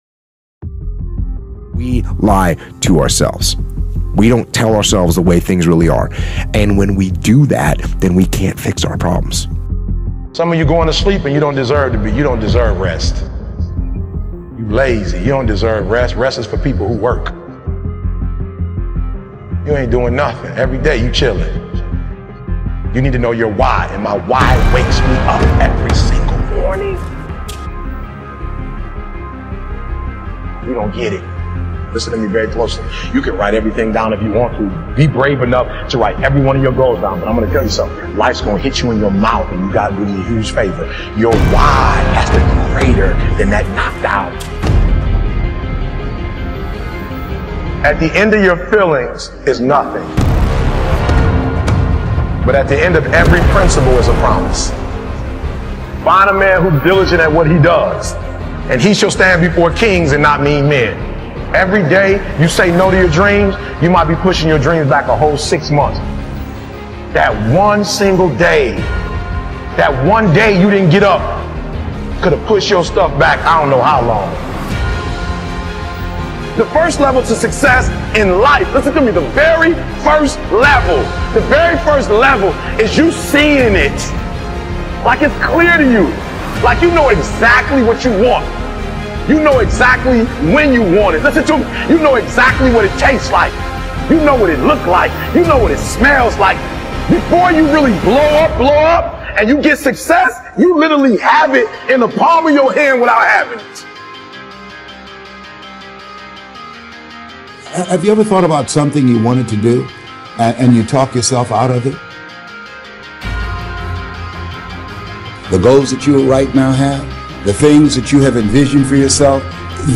Master Your Thoughts: Morning Discipline with Epic Music to Crush Mental Blocks